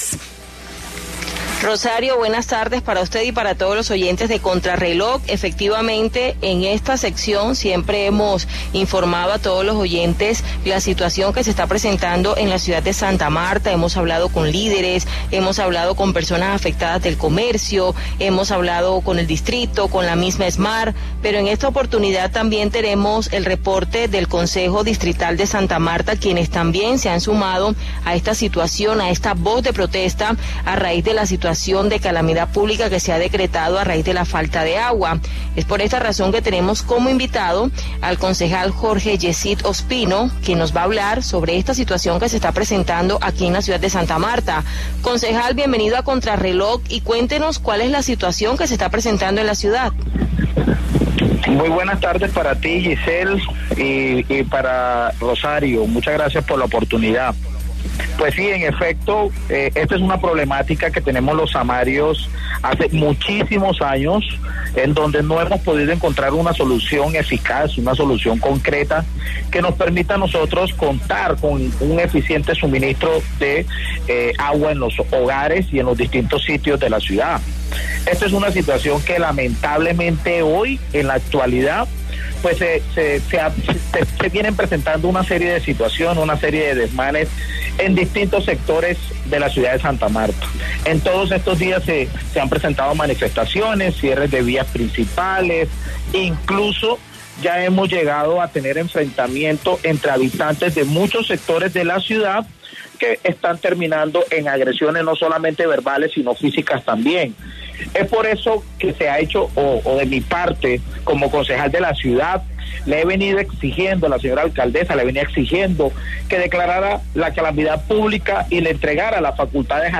En entrevista en Contrarreloj, aseguró que, lo más importante es garantizar el servicio a los samarios independientemente de las diferencias políticas que existan.